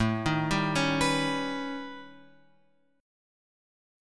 A 9th Flat 5th